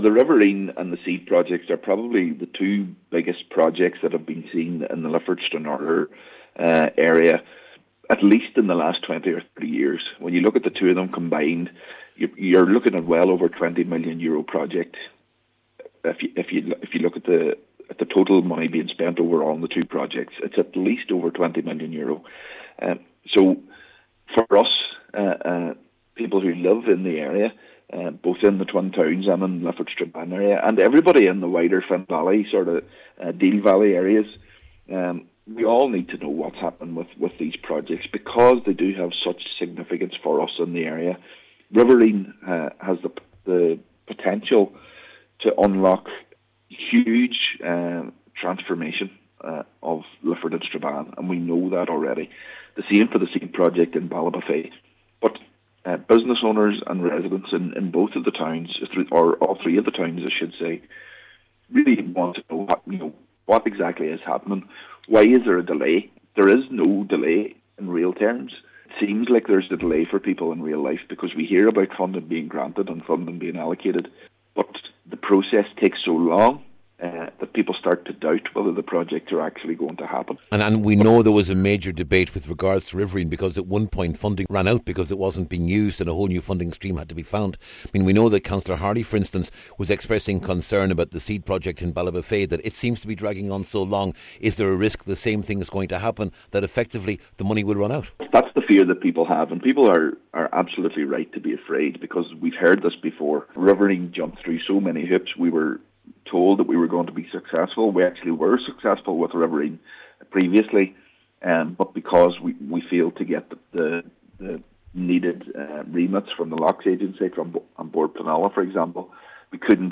Cllr Doherty says particularly after temporarily losing the Riverine funding because of failure to secure foreshore licences, it’s vital these projects are progressed properly, and regular updates are provided……